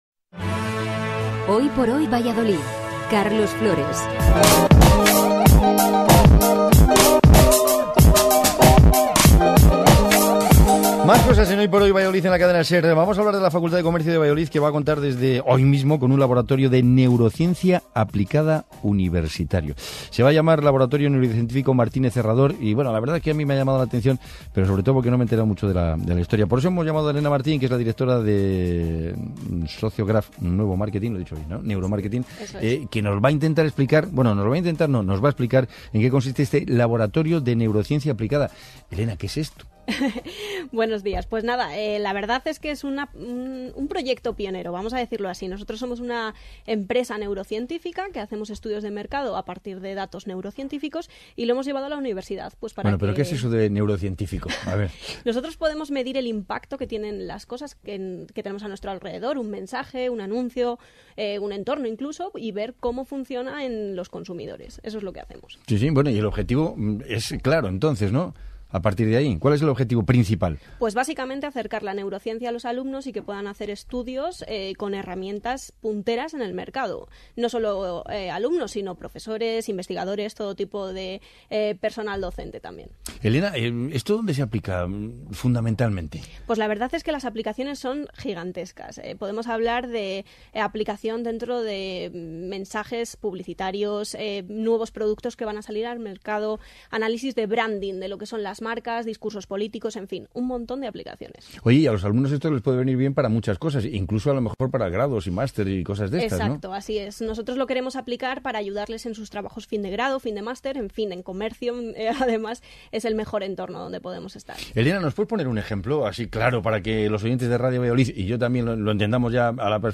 cadenaser_entrevista.mp3